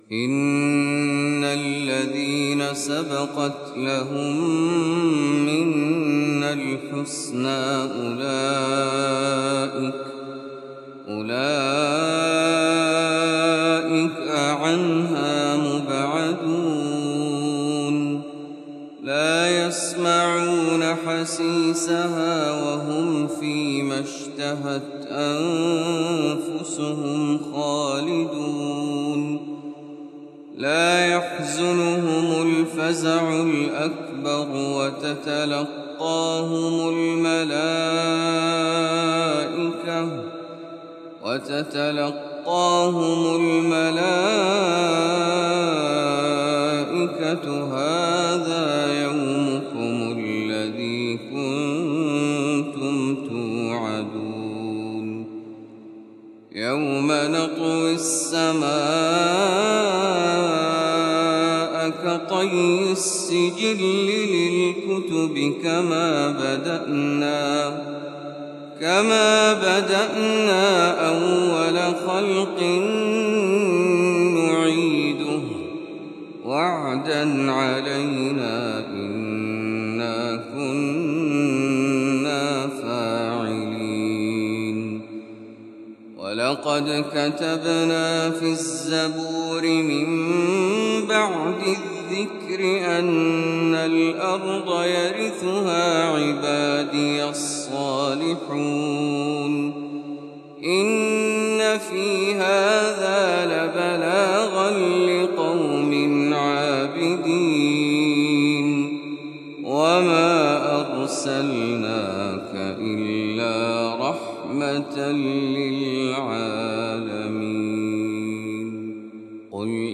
تلاوة محبرة خاشعة